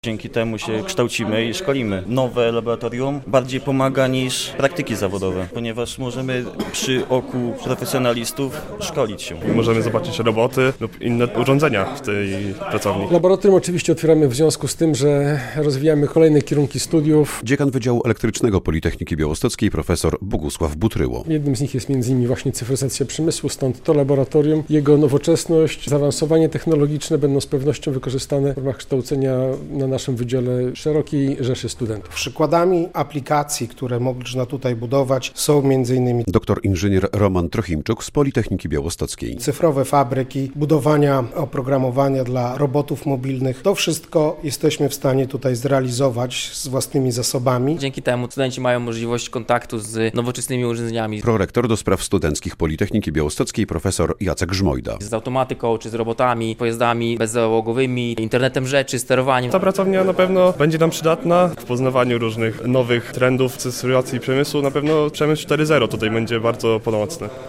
Nowe Laboratorium Przemysłowej Autonomizacji i Cyfrowej Produkcji na Politechnice Białostockiej - relacja